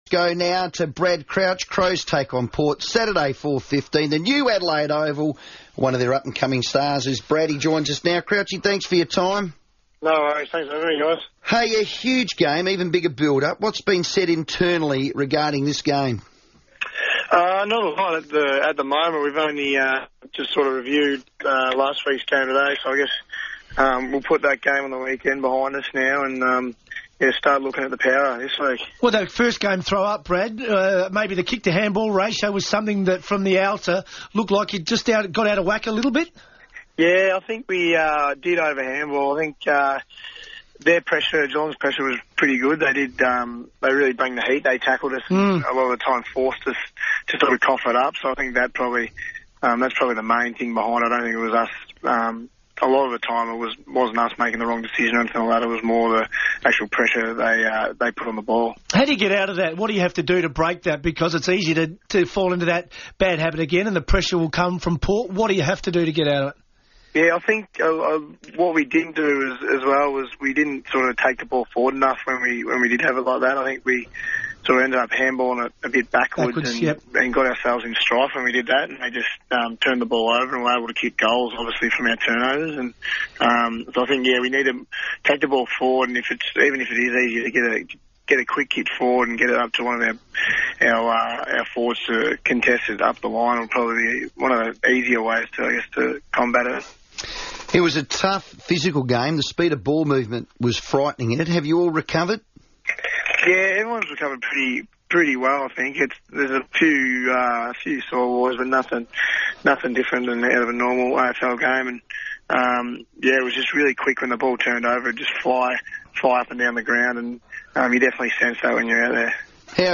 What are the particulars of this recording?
on the FIVEaa sports show